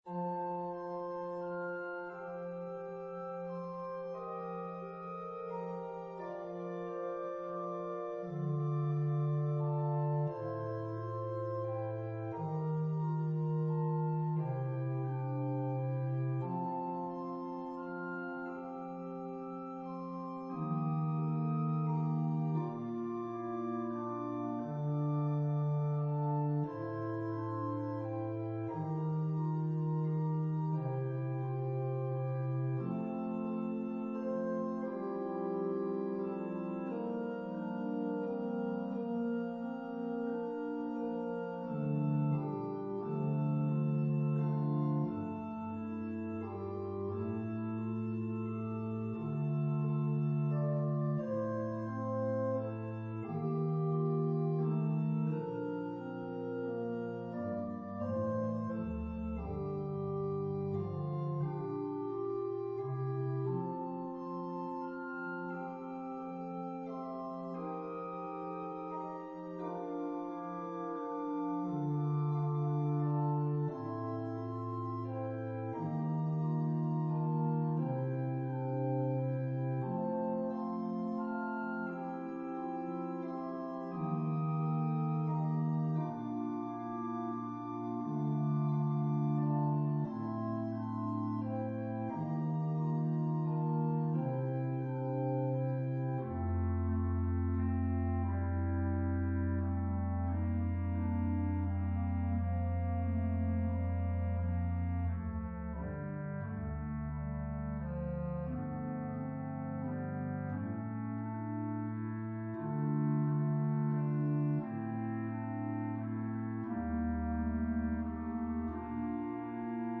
An organ solo arrangement
Voicing/Instrumentation: Organ/Organ Accompaniment